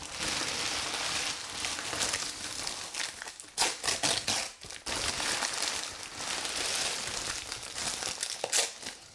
Бумага звуки скачать, слушать онлайн ✔в хорошем качестве